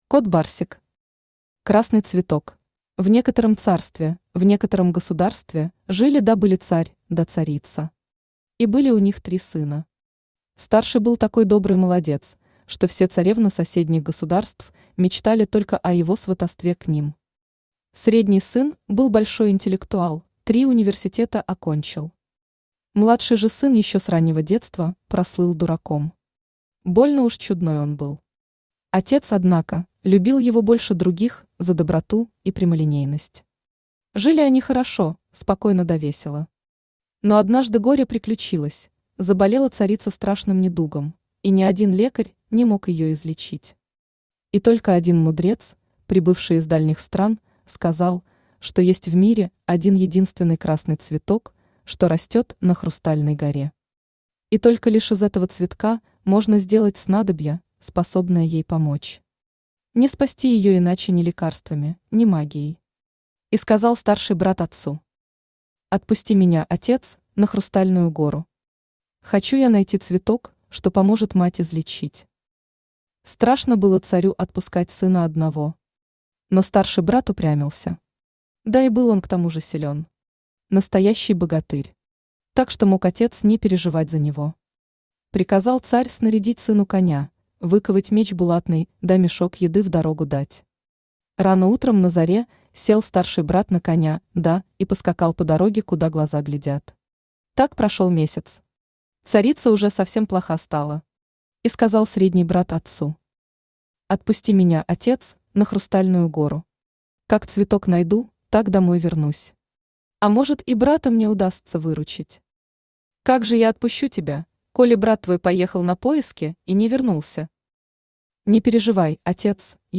Aудиокнига Красный цветок Автор Кот Барсик Читает аудиокнигу Авточтец ЛитРес.